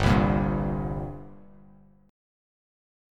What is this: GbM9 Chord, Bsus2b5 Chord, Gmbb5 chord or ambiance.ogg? Gmbb5 chord